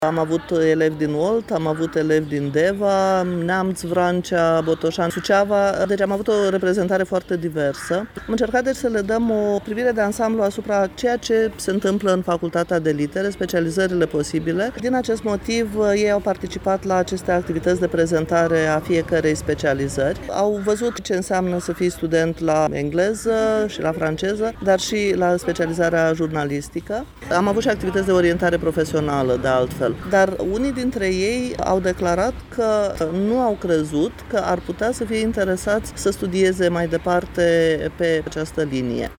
Într-o declarație acordată postului nostru de radio